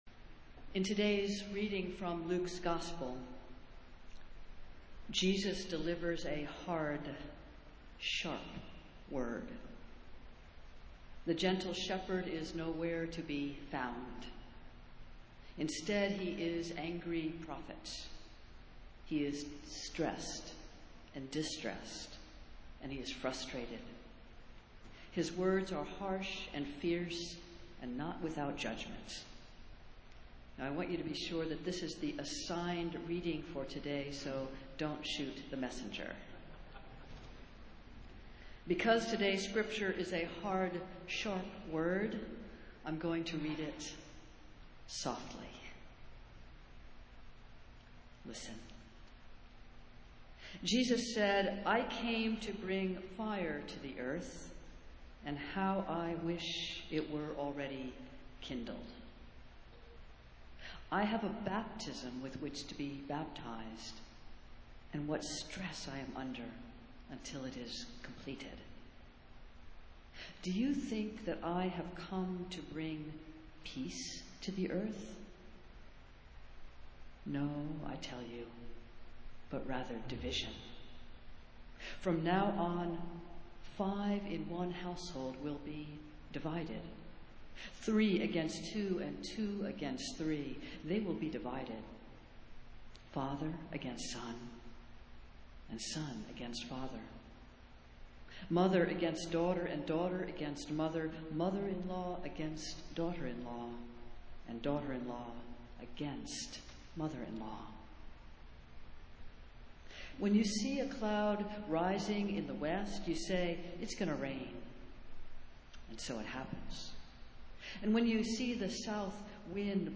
Festival Worship - Thirteenth Sunday after Pentecost